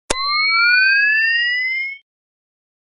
Night Vision Power Up
SFX
yt_RTicVenTq4s_night_vision_power_up.mp3